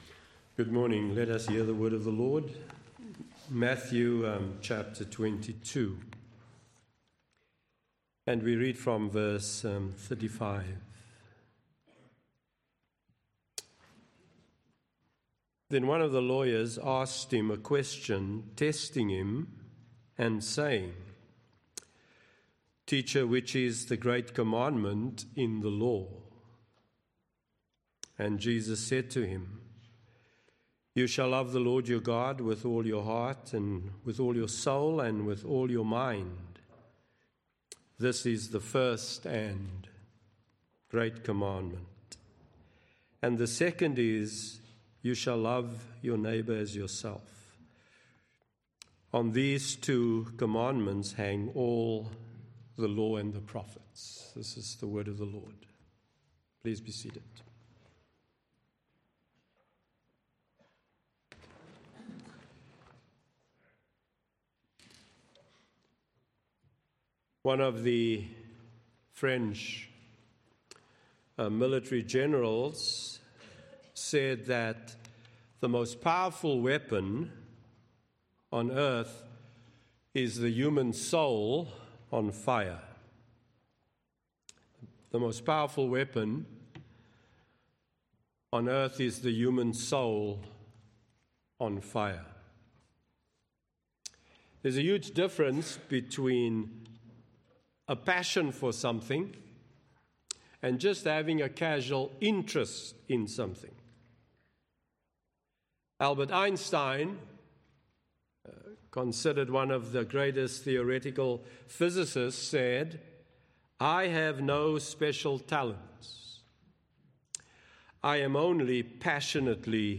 From Series: "Other Sermons"